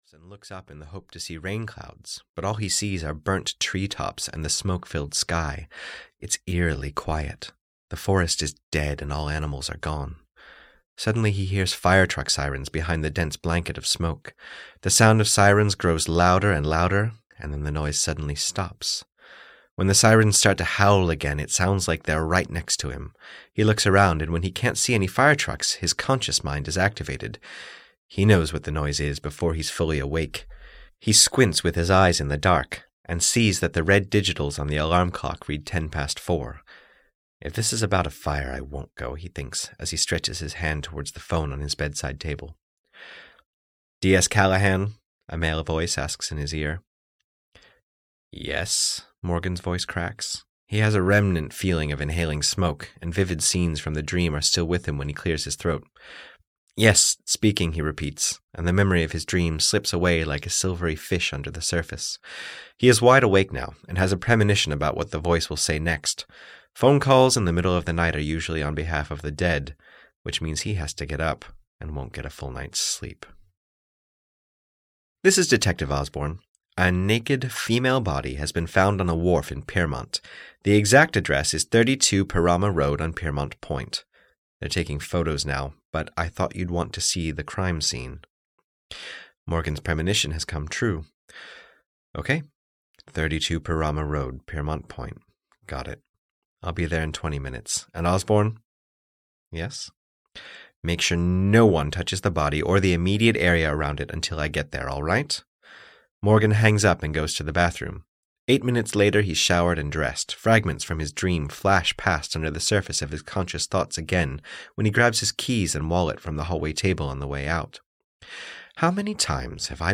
GAME (EN) audiokniha
Ukázka z knihy